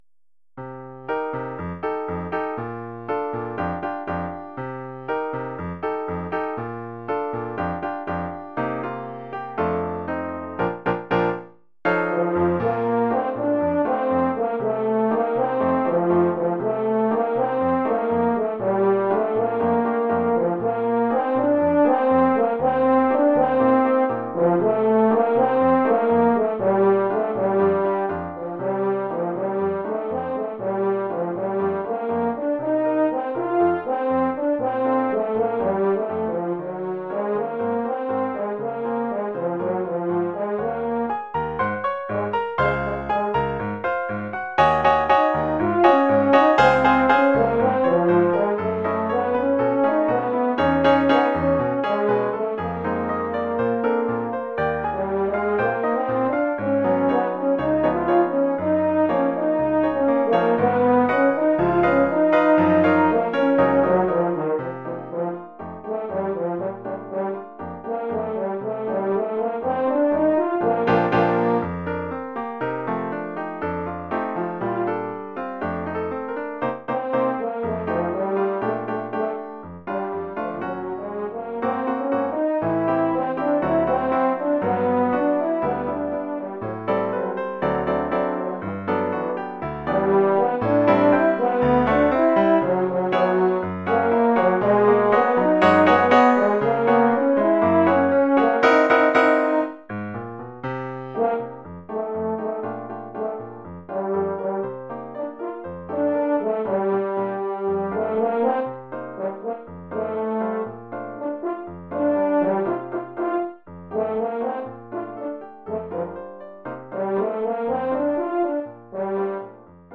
Oeuvre pour saxhorn alto et piano.